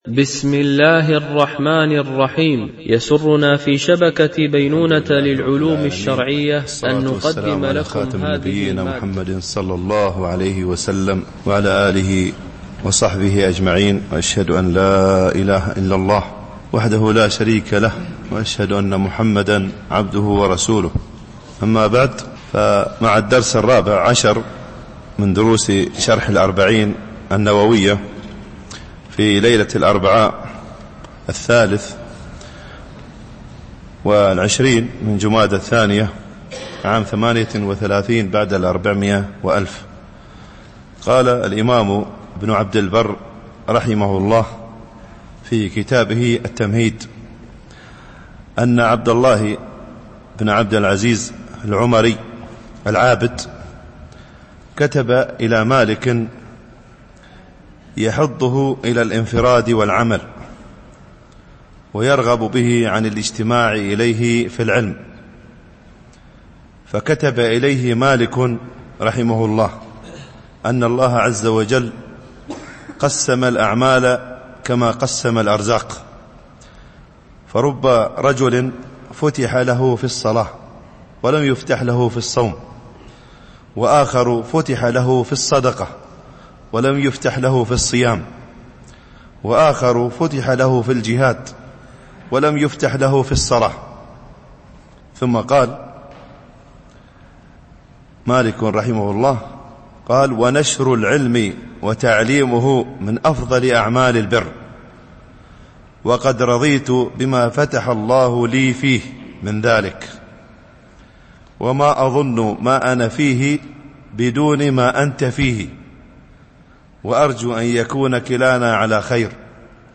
شرح الأربعين النووية ـ الدرس 14 (الحديث 5)